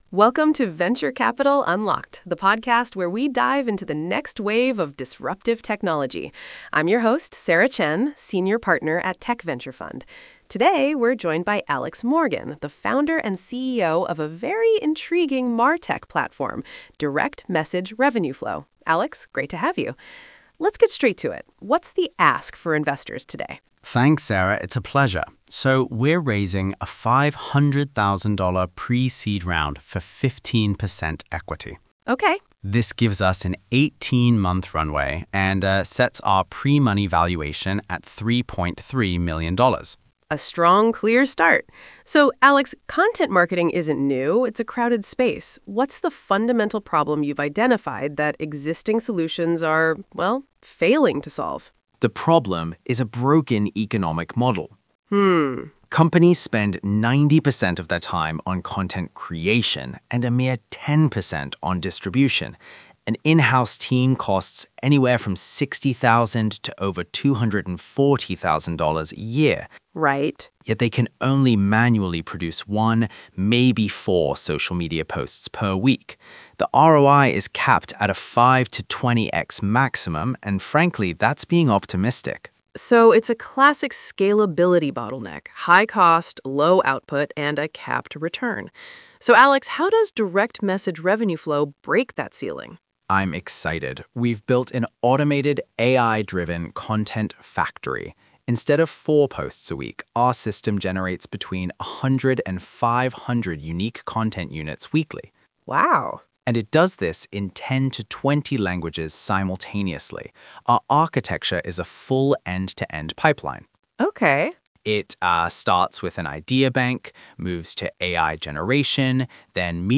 Investment Pitch Podcast
Pro Tip: Press play on the podcast, then open the pitch deck — the audio narrates each slide in sequence for the best investor experience.